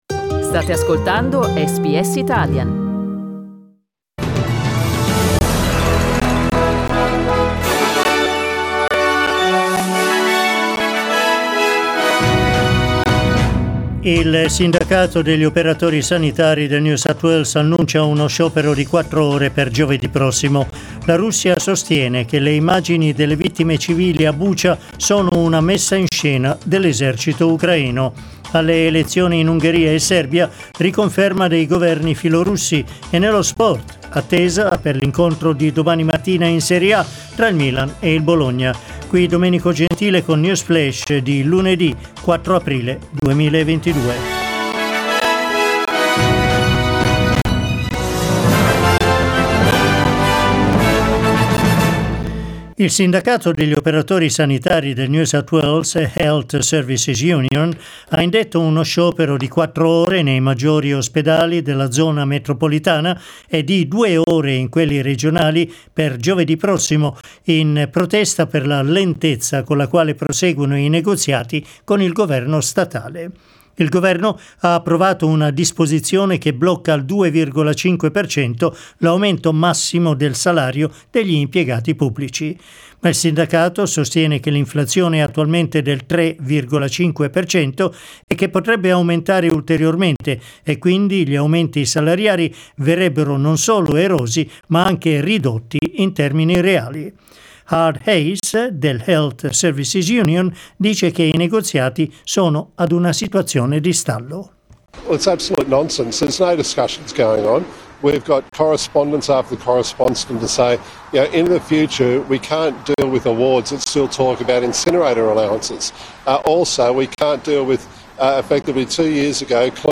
News flash lunedì 4 aprile 2022